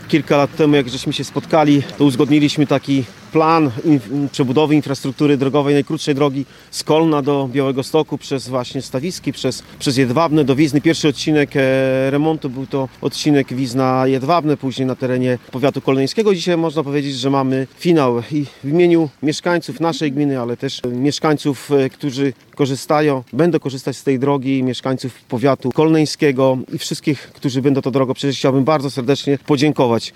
Burmistrz Jedwabnego i prezes „Łomżyńskiego Forum Samorządowego” Adam Niebrzydowski, w imieniu mieszkańców, dziękował wszystkim, którzy przyczynili się do wykonania remontu drogi.